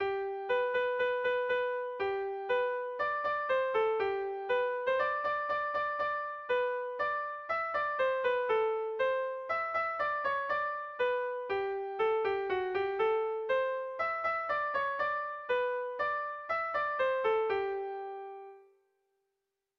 Irrizkoa
Zortziko txikia (hg) / Lau puntuko txikia (ip)
A-B-C-D